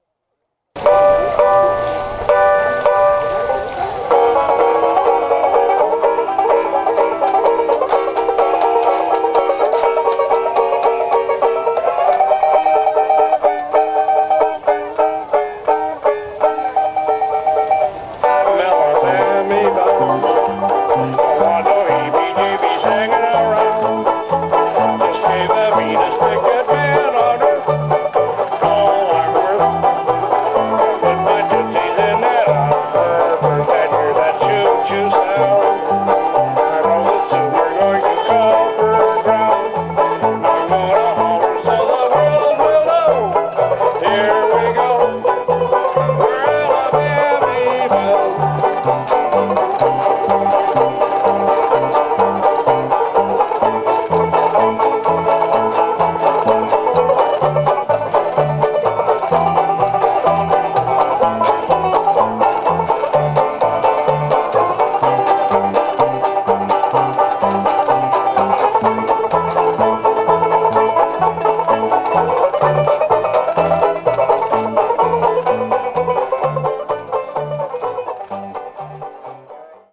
Listen to the WineLand Banjo Band perform "Alabamy Bound" (mp3)